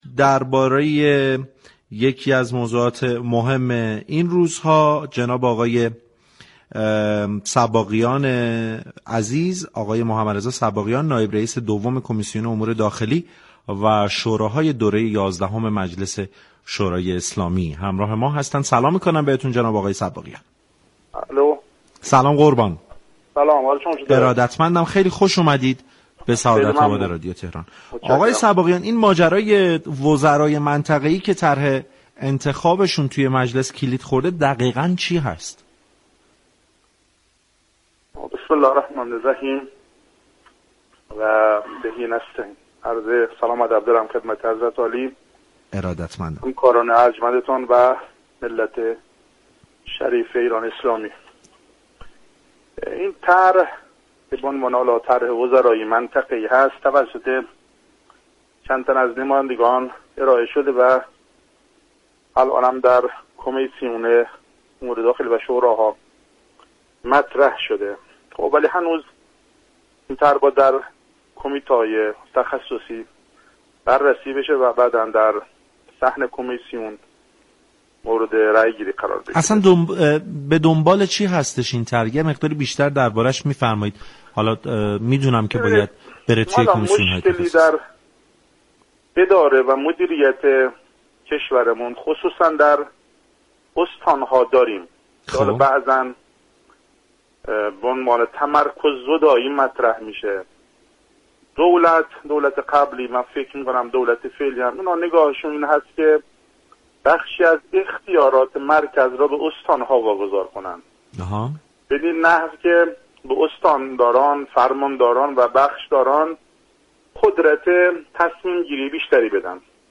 به گزارش پایگاه اطلاع رسانی رادیو تهران، محمدرضا صباغیان نایب رئیس دوم كمیسیون امور داخلی و شوراهای مجلس یازدهم در گفتگو با برنامه سعادت آباد رادیو تهران درباره ماجرای وزرای منطقه ای كه طرح آن در مجلس كلید خورده است گفت: این طرح توسط چندتن از نمایندگان ارائه و در كمیسیون امور داخلی و شوراهای مجلس مطرح شده تا با پس از بررسی در كمیسیون های تخصصی در صحن مجلس به رأی گذارده شود.